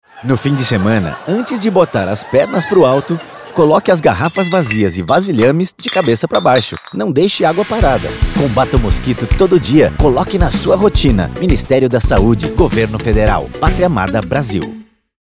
Spot - Mosquito Garrafa